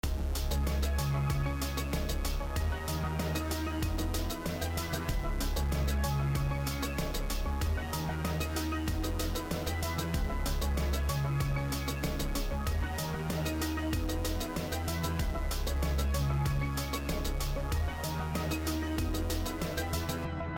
80ssynth.mp3